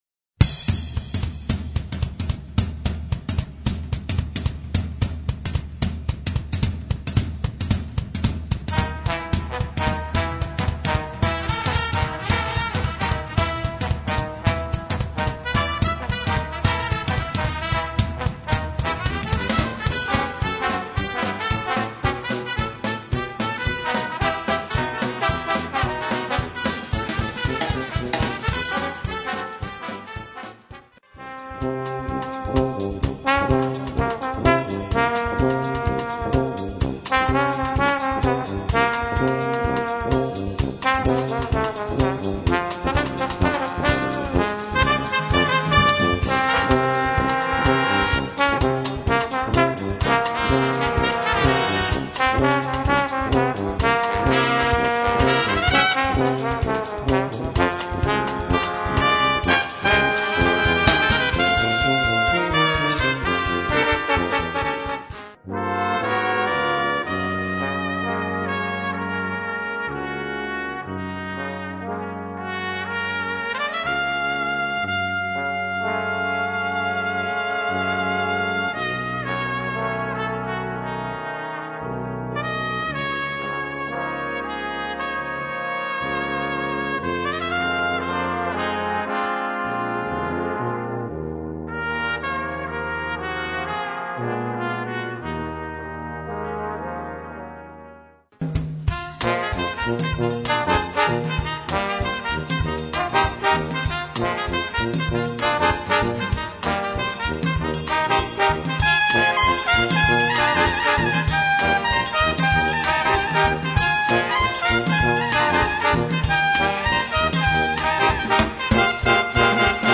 brass quintet
elegant, smooth sounding dance band